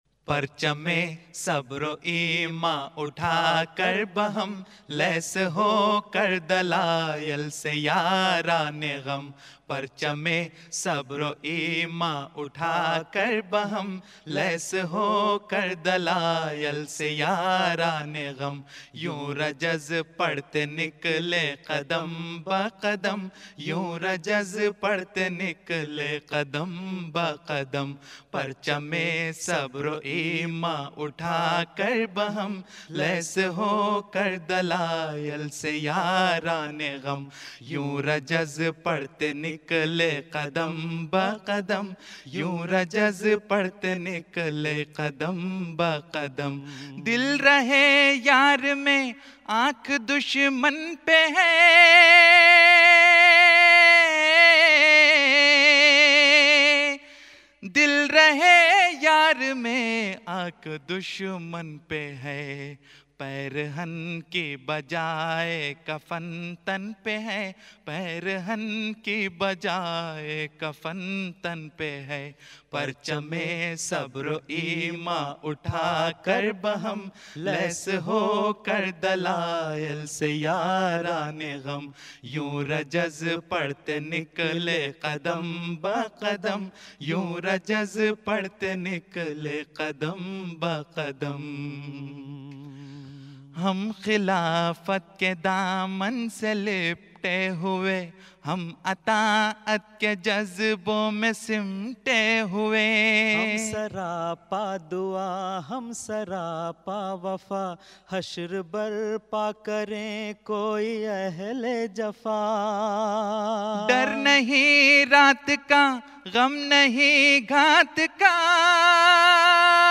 Voice: Group
(UK Jalsa)